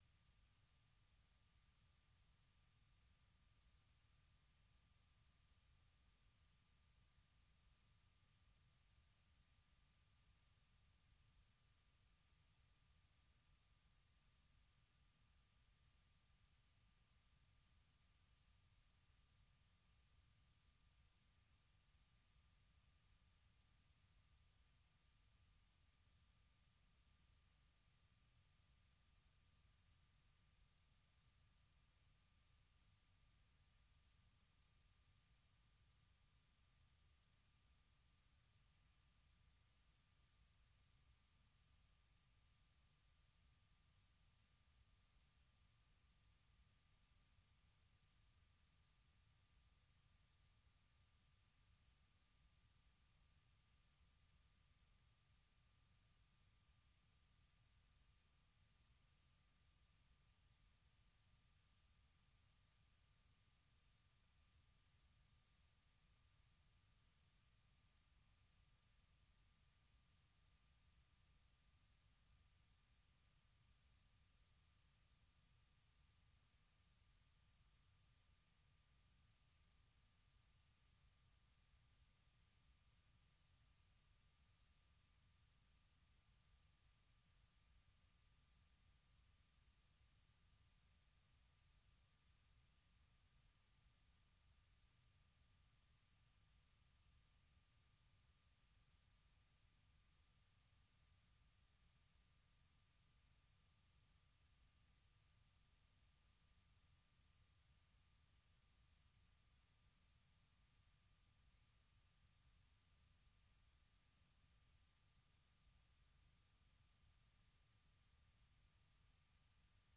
Le Monde au Féminin est une émission interactive